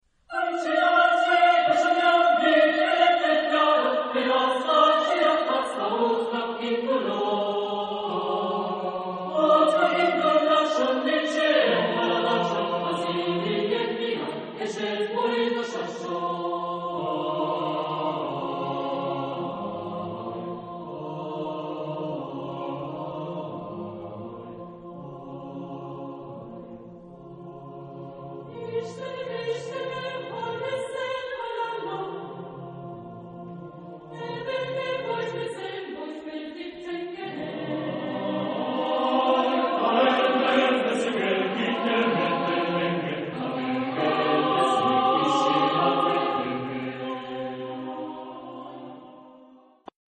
Genre-Style-Form: Secular ; Folk music
Type of Choir: SATB  (4 mixed voices )